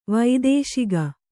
♪ vaidēśiga